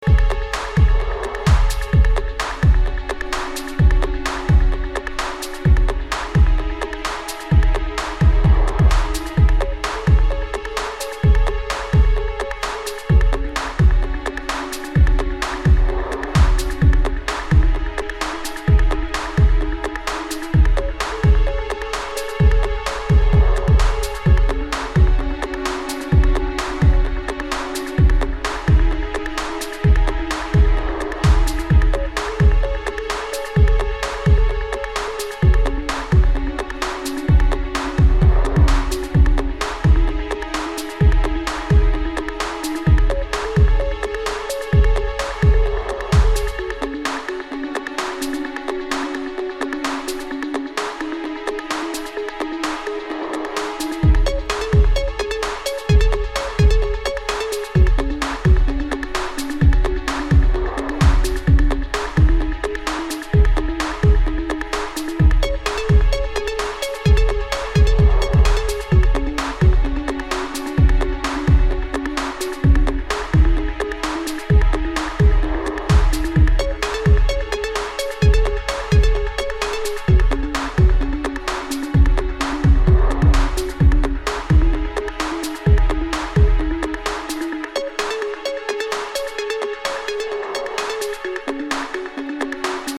Modern techno tools